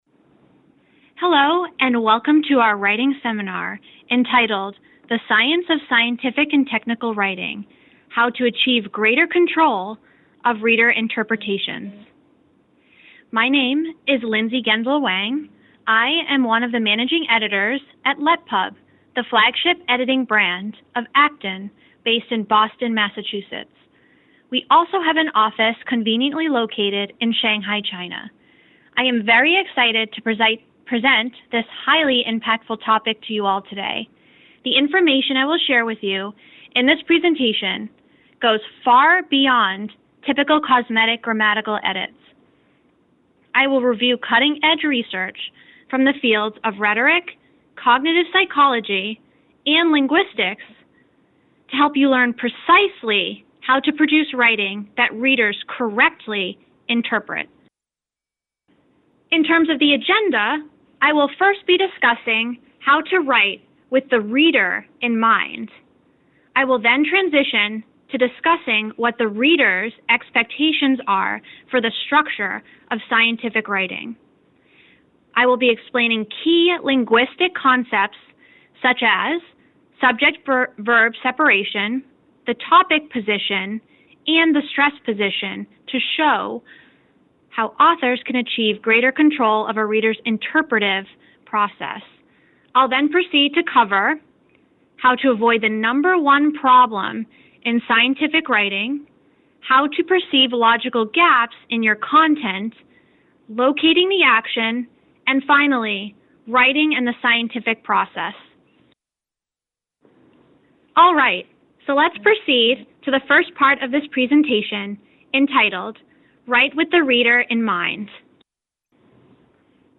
12月6日晚，LetPub联合丁香园成功为广大医学和生物领域科研学者举办了一堂与科技写作相关的微信公开课，共吸引了500位科研人员参加。